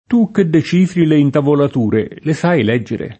l$SSi]; part. pass. letto [l$tto] — es. con acc. scr.: tu che decifri le intavolature, le sai lèggere? [
t2 kke dde©&fri le intavolat2re, le S#i l$JJere?] (D’Annunzio); che cosa lègge di bello? [ke kk0Sa l$JJe di b$llo?] (Cicognani)